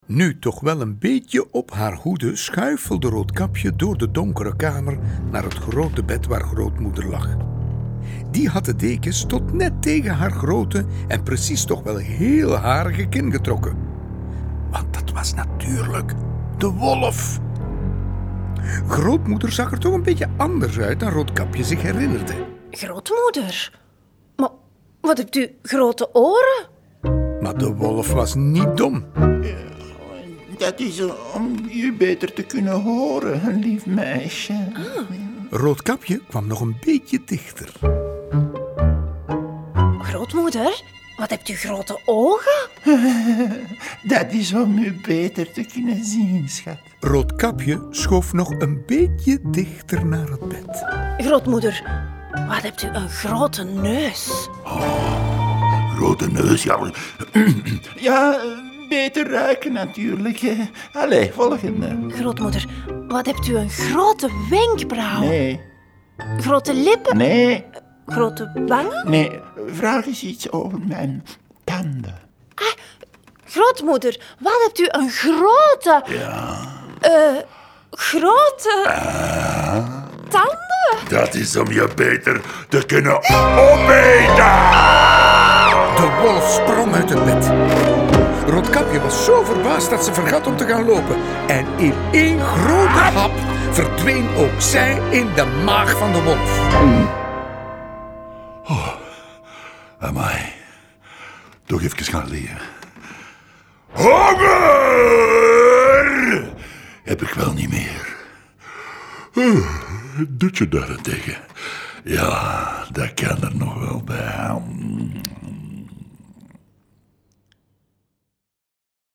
Je kan deze verhalen zelf lezen of voorlezen.